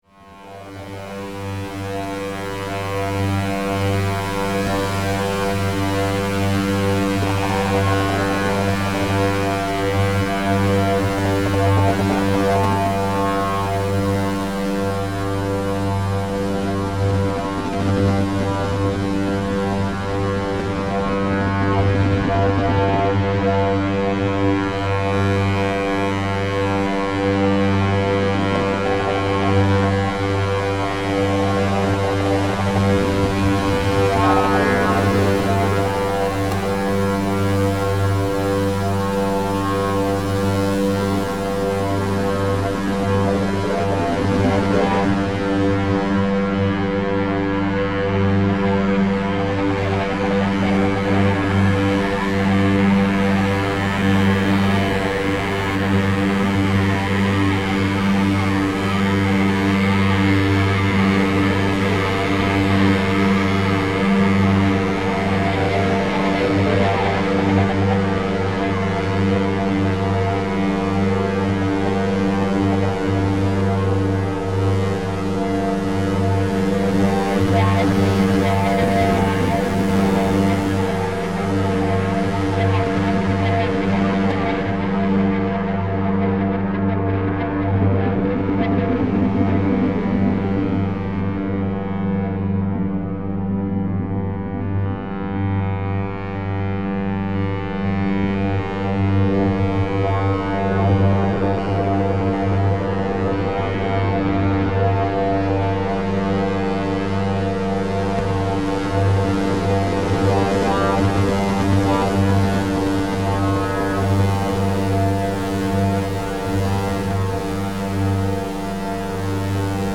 "XPO Drone Test"
One analog stereo oscillator with multiple outs to various fx-units droning.
XPODroneTest.mp3